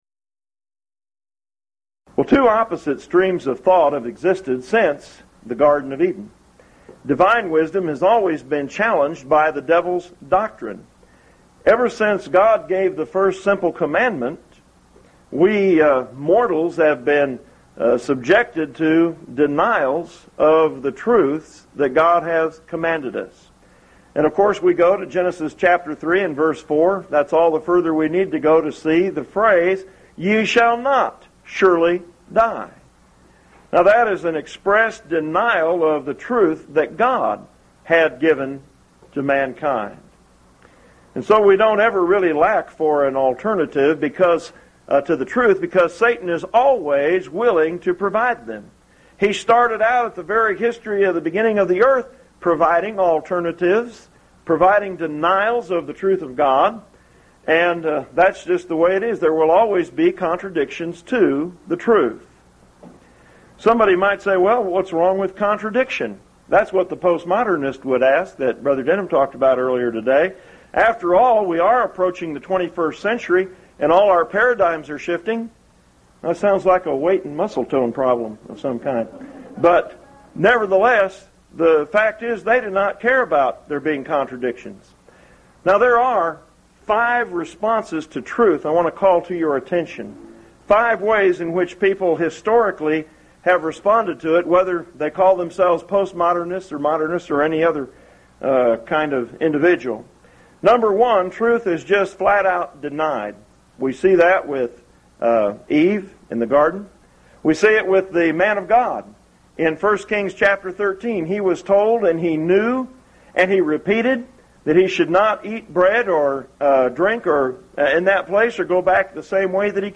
Event: 1st Annual Lubbock Lectures
If you would like to order audio or video copies of this lecture, please contact our office and reference asset: 1998Lubbock18